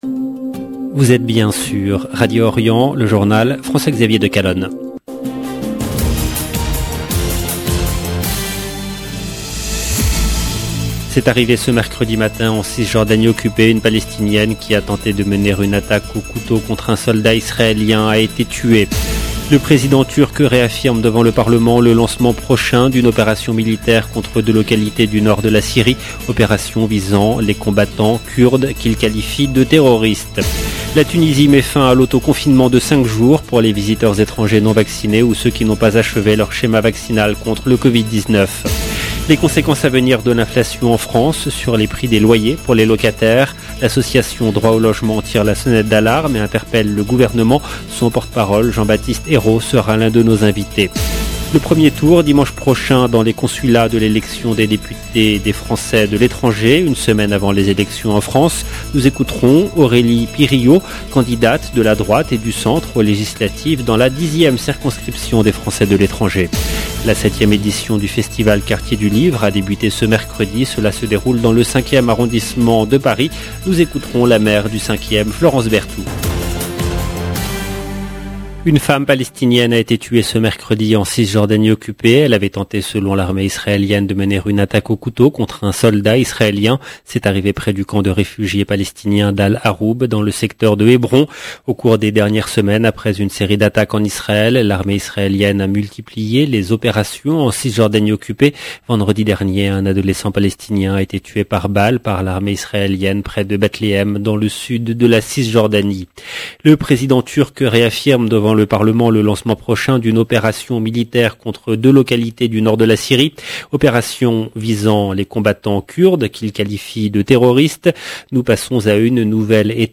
LE JOURNAL DU SOIR EN LANGUE FRANCAISE DU 1/06/22 LB JOURNAL EN LANGUE FRANÇAISE
Nous écouterons la maire Florence Berthout. 0:00 16 min 59 sec